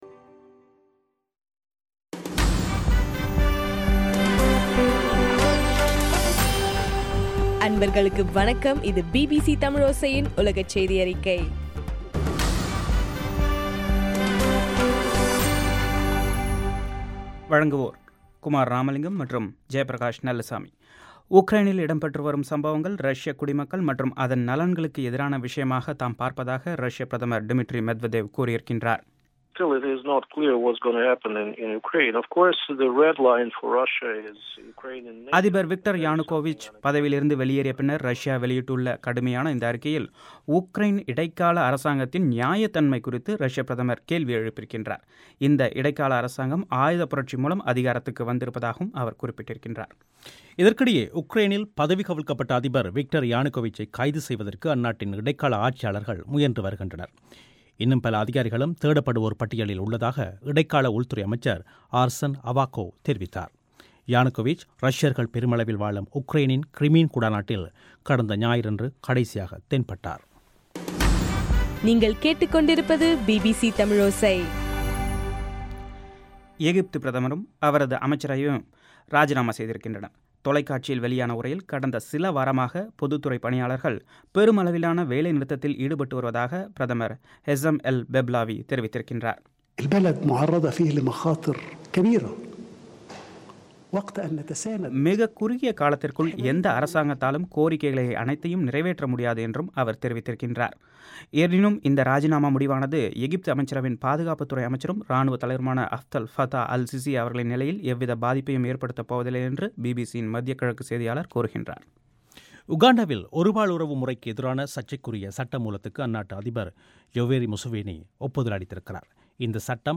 பிப்ரவரி 24 பிபிசியின் உலகச் செய்திகள்